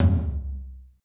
Index of /90_sSampleCDs/Northstar - Drumscapes Roland/DRM_Techno Rock/TOM_F_T Toms x
TOM F T L03R.wav